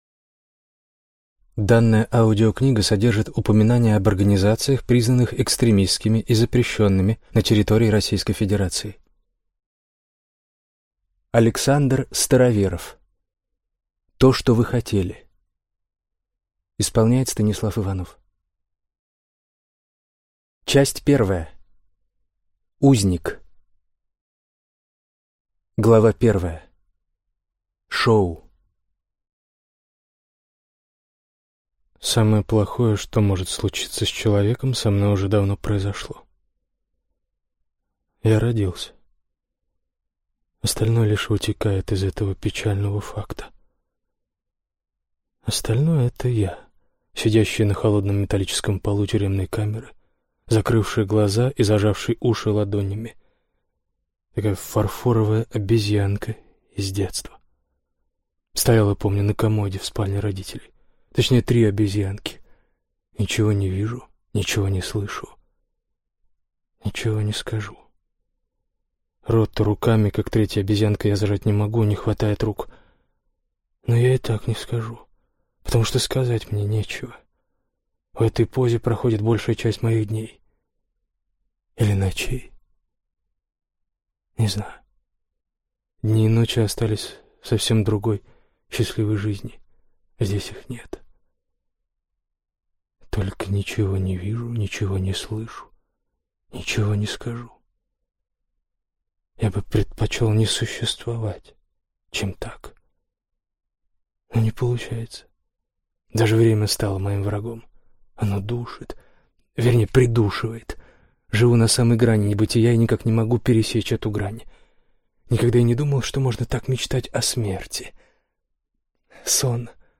Аудиокнига То, что вы хотели | Библиотека аудиокниг
Прослушать и бесплатно скачать фрагмент аудиокниги